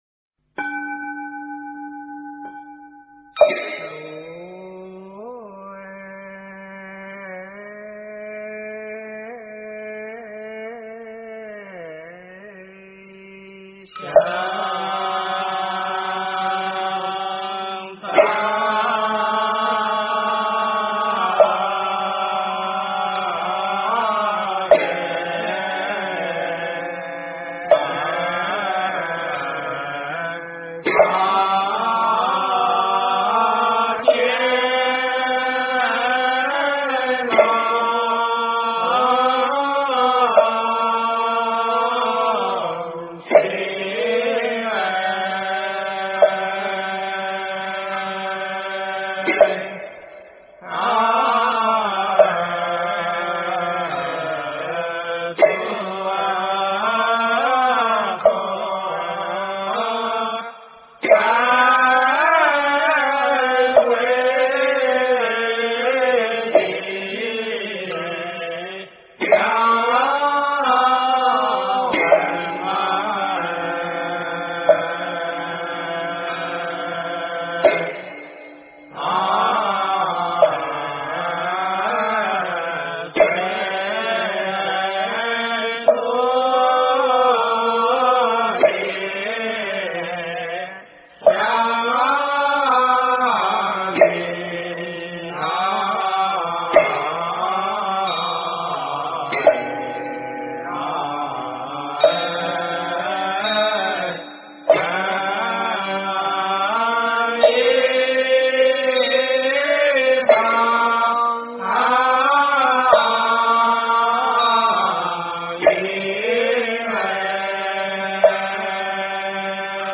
炉香赞--文殊院 经忏 炉香赞--文殊院 点我： 标签: 佛音 经忏 佛教音乐 返回列表 上一篇： 晚课--万佛城 下一篇： 六字大明咒--心定和尚 相关文章 大乘金刚般若宝忏法卷中--金光明寺 大乘金刚般若宝忏法卷中--金光明寺...